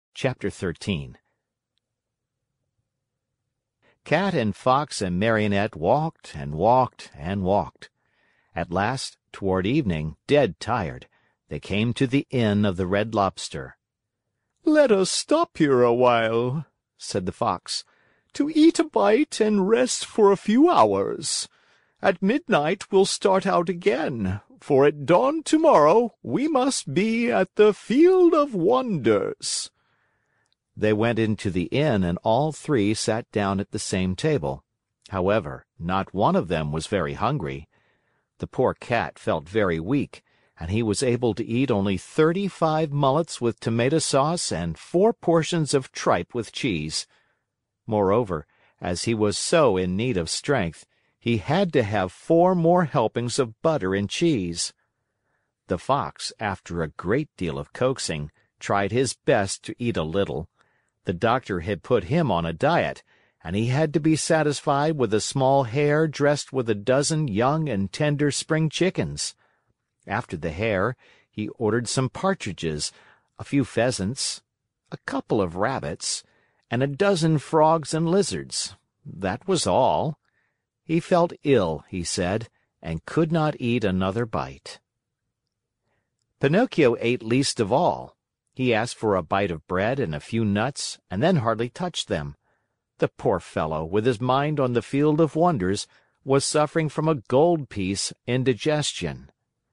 在线英语听力室木偶奇遇记 第28期:红龙虾旅馆的听力文件下载,《木偶奇遇记》是双语童话故事的有声读物，包含中英字幕以及英语听力MP3,是听故事学英语的极好素材。